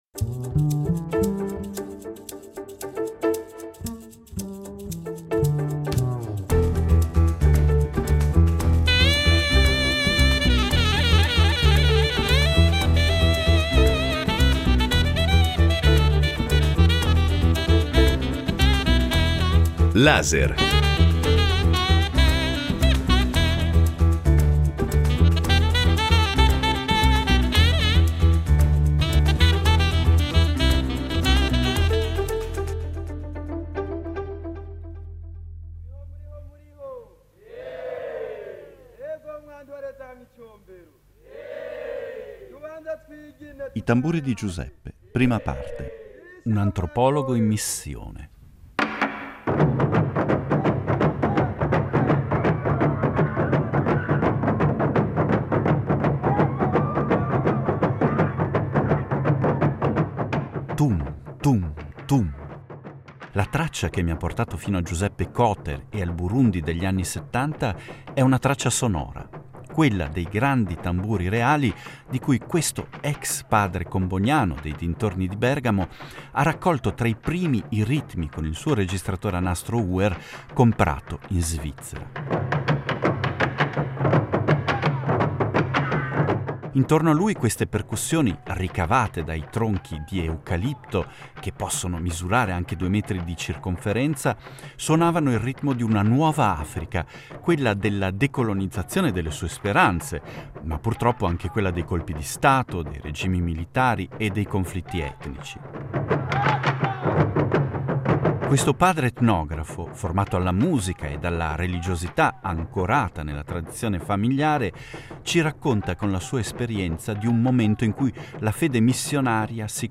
Al ritmo dei grandi tamburi del Burundi, che dal 2014 sono entrati a far parte del patrimonio culturale immateriale dell’umanità UNESCO.
Suoni tratti dagli archivi radiofonici della RSI